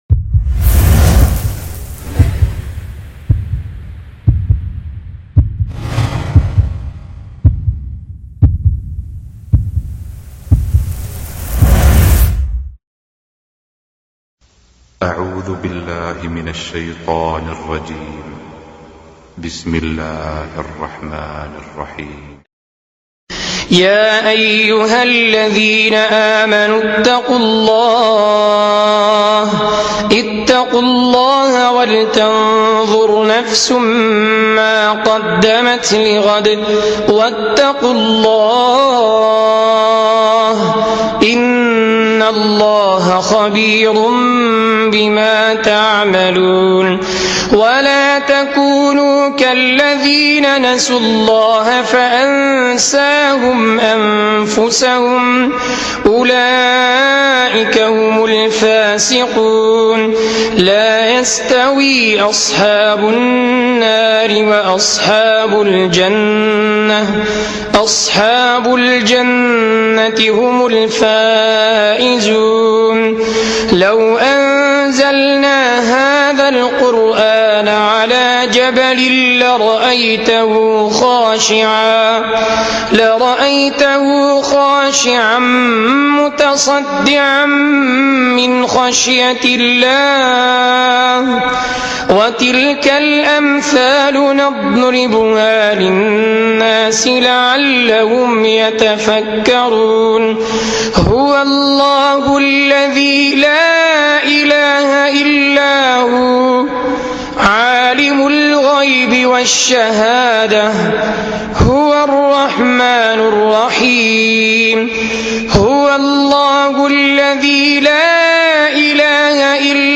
Beautiful Quran Recitation: Surah Al Hashr 18-24
This video is a Qur’an recitation of verses 18-24 from Surat Al-Hashr, with spectacular effects and timelapses.